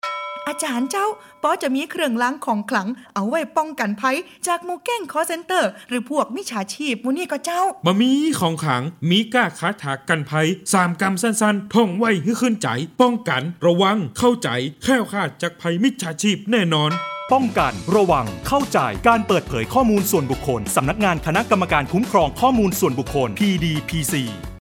ภาคเหนือ
ภาคเหนือ.mp3